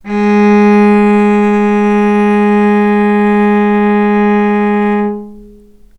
healing-soundscapes/Sound Banks/HSS_OP_Pack/Strings/cello/ord/vc-G#3-mf.AIF at 48f255e0b41e8171d9280be2389d1ef0a439d660
vc-G#3-mf.AIF